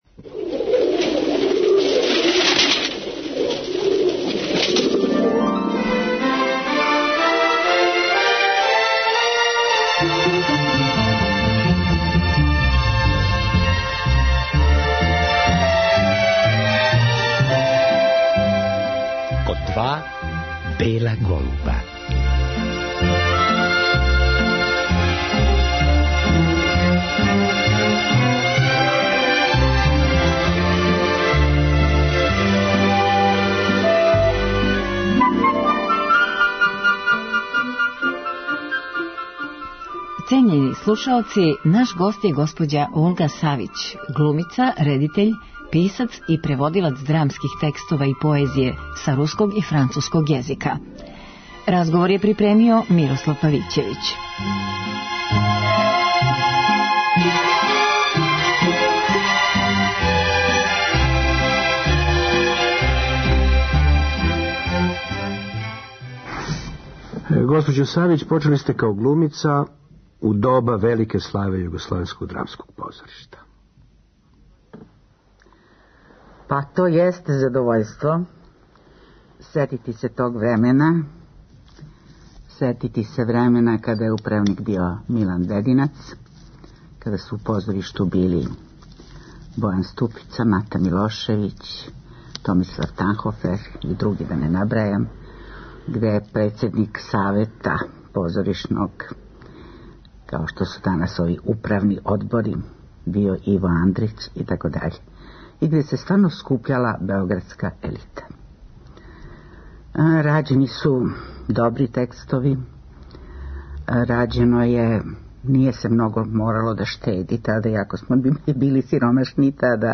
Разговор са глумицом